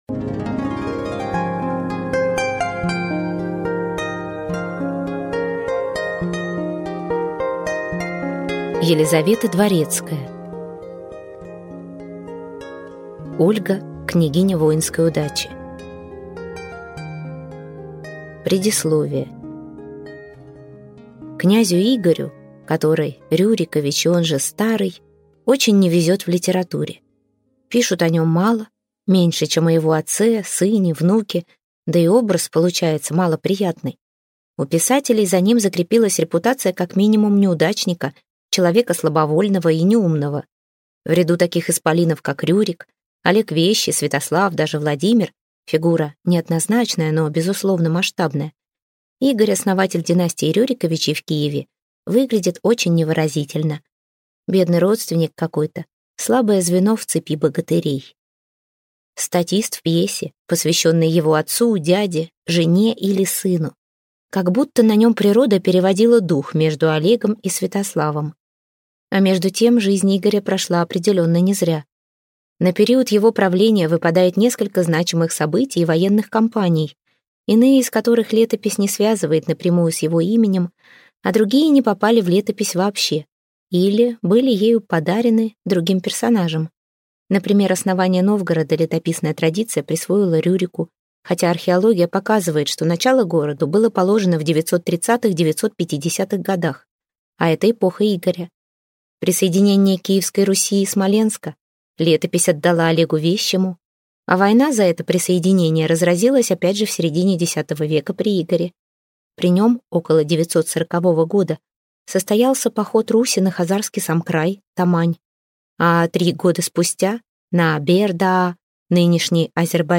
Аудиокнига Ольга, княгиня воинской удачи | Библиотека аудиокниг